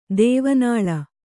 ♪ dēva nāḷa